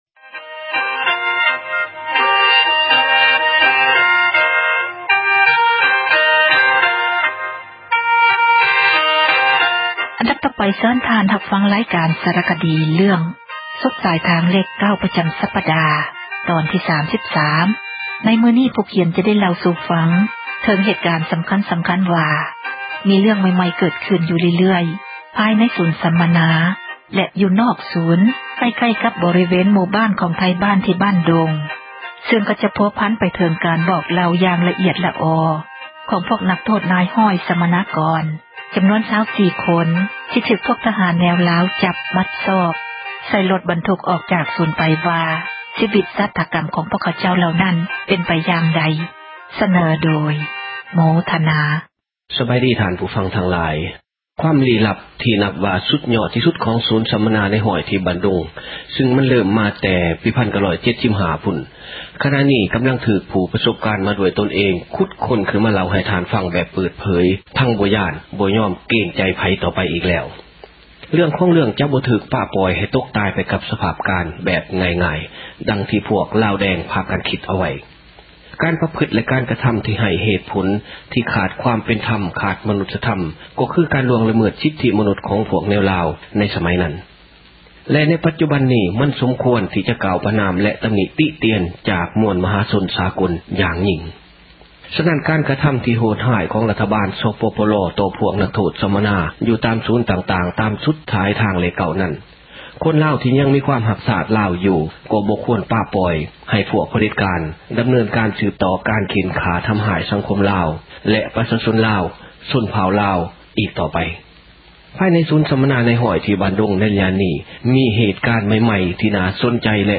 ຣາຍການ ສາຣະຄະດີ ເຣື້ອງ ”ສຸດສາຍທາງເລຂ 9” ປະຈຳສັປດາ ຕອນທີ 33.